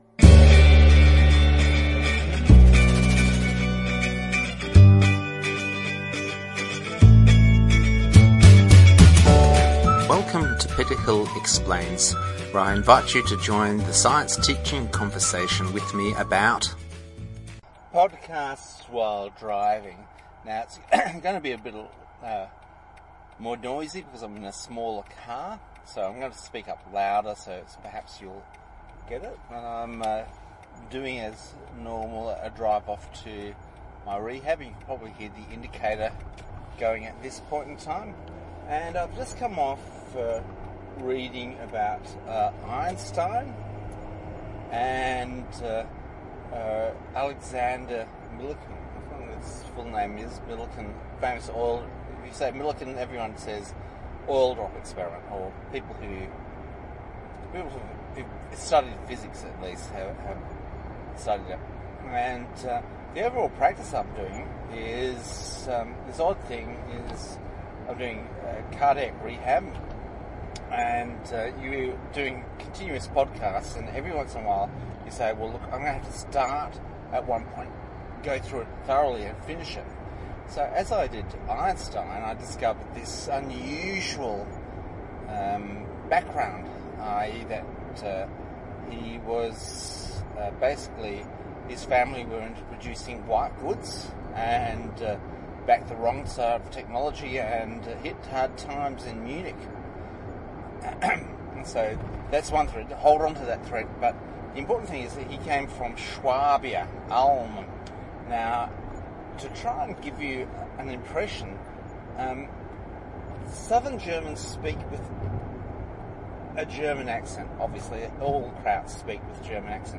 This podcast while driving looks as getting into the feel of the times, and behind the scenes.